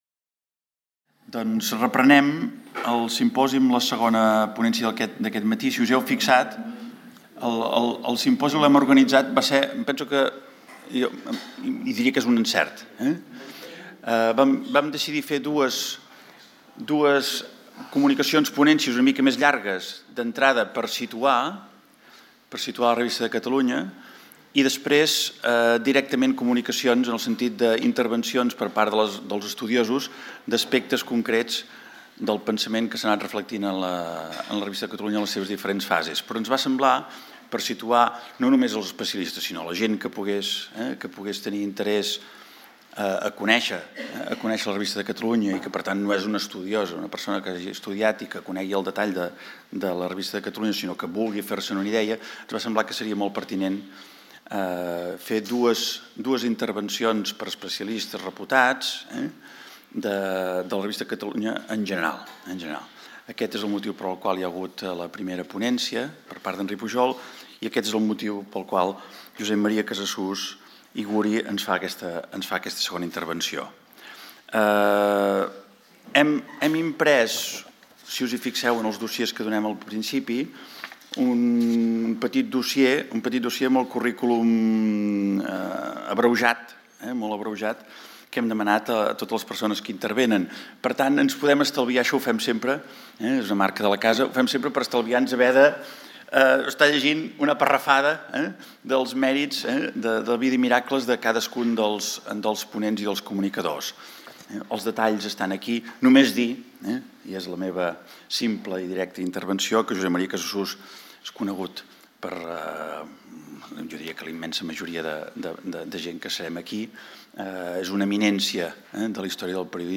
Conferència
en el marc del Simposi Trias 2024 sobre el centenari de la Revista de Catalunya